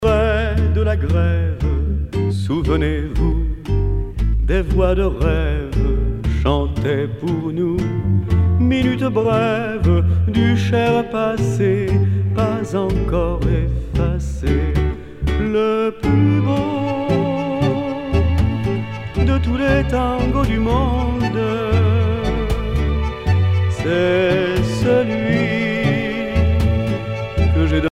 danse : tango
Genre strophique
Pièce musicale éditée